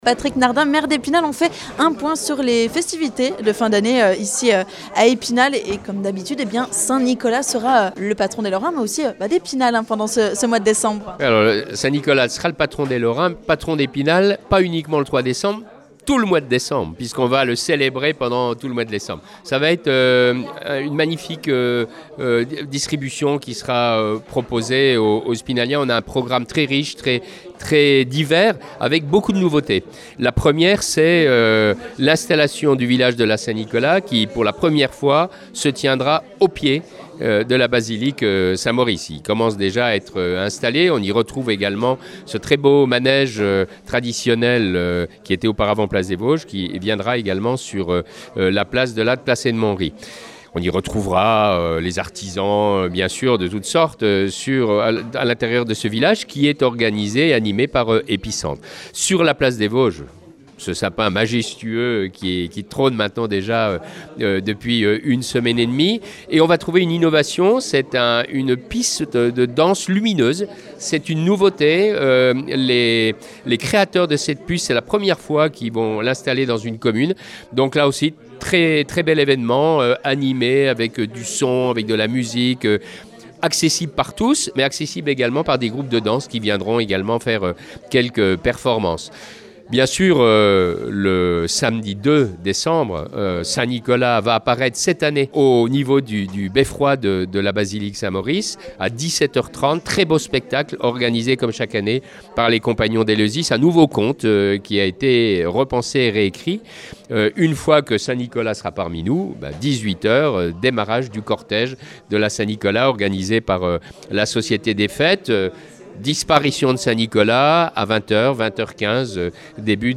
Pour tout savoir, écoutez ce podcast avec le maire d'Epinal, Patrick Nardin !